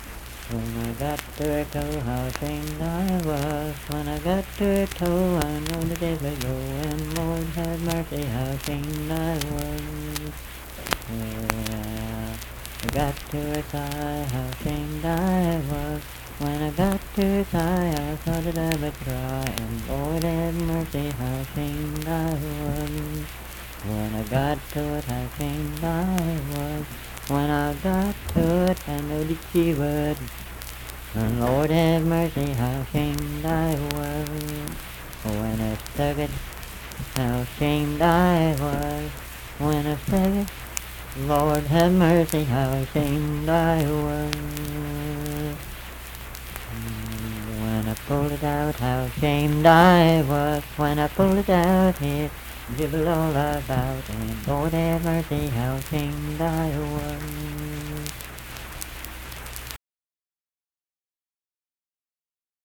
Unaccompanied vocal music
Performed in Dundon, Clay County, WV.
Bawdy Songs
Voice (sung)